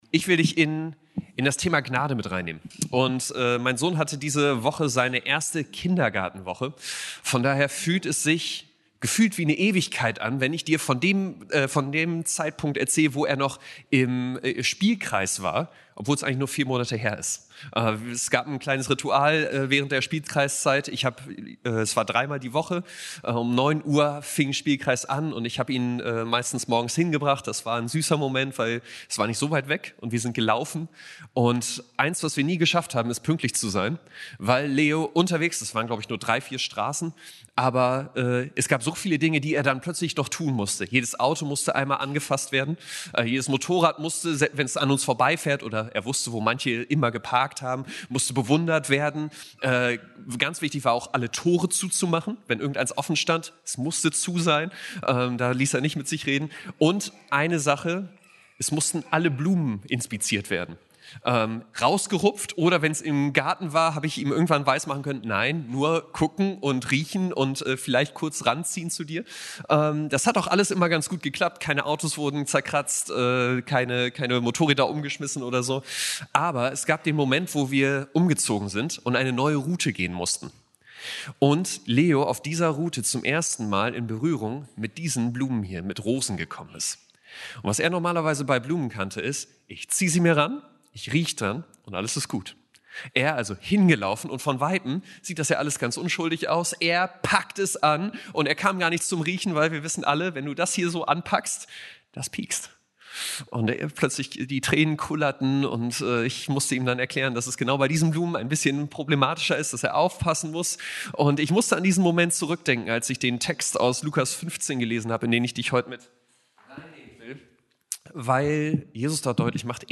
Serie: Standortgottesdienste